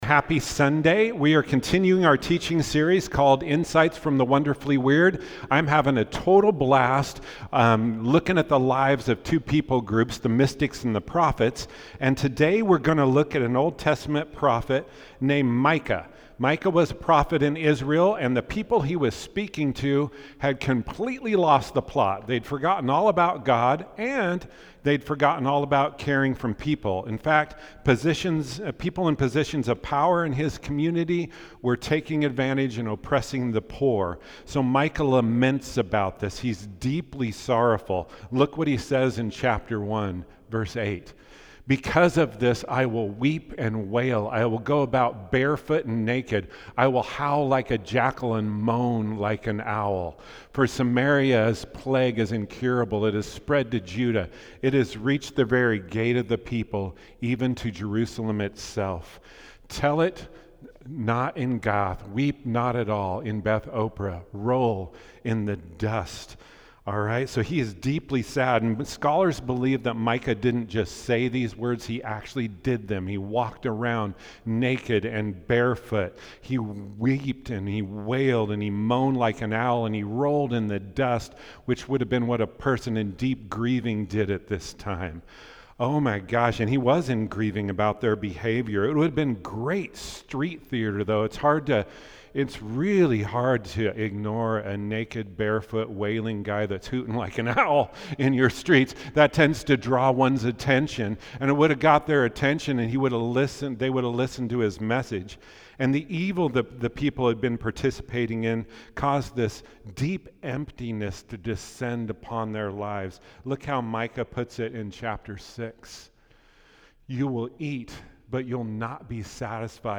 2025 Current Sermon Insights from the Wonderfully Weird!